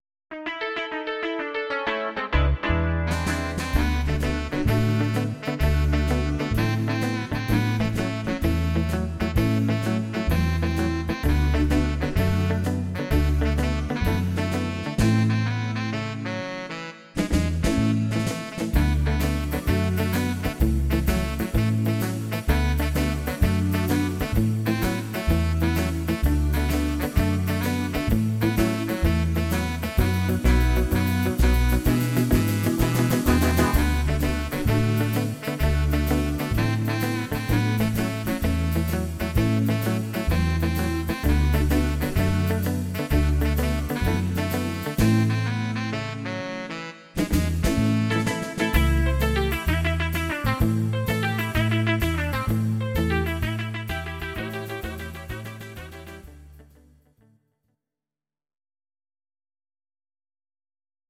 Audio Recordings based on Midi-files
Pop, Oldies, 1950s